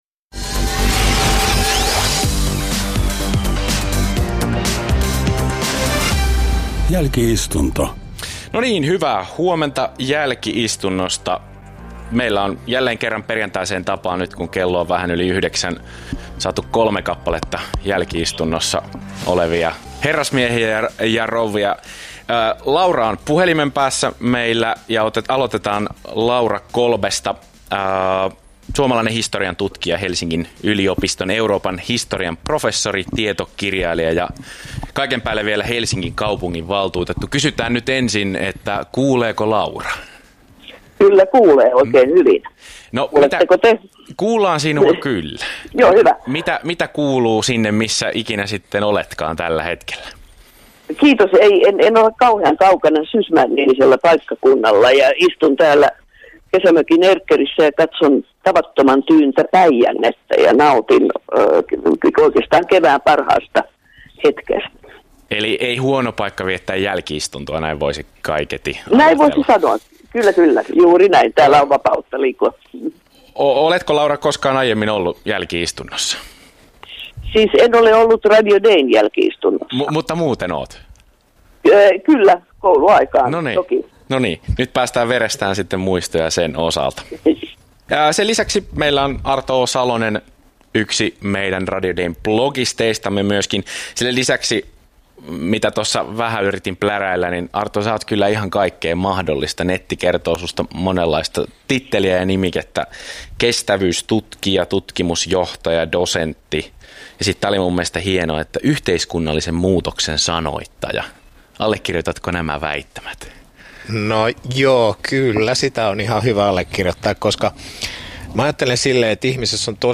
Radio Dein perjantaisessa jälki-istunnossa olivat keskustelemassa puhelimitse